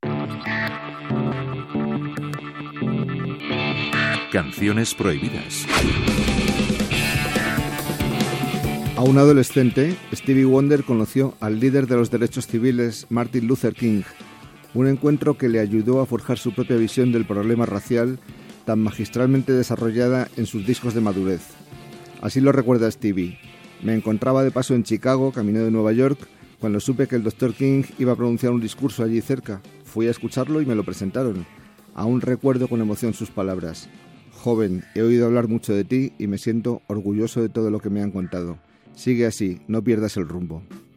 Indicatiu del programa, informació sobre el cantant Stevie Wonder.
Musical